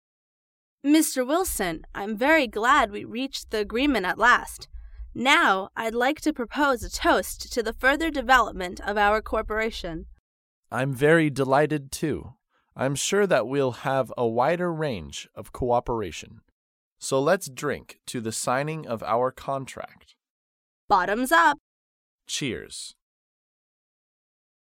在线英语听力室高频英语口语对话 第247期:喝酒庆祝的听力文件下载,《高频英语口语对话》栏目包含了日常生活中经常使用的英语情景对话，是学习英语口语，能够帮助英语爱好者在听英语对话的过程中，积累英语口语习语知识，提高英语听说水平，并通过栏目中的中英文字幕和音频MP3文件，提高英语语感。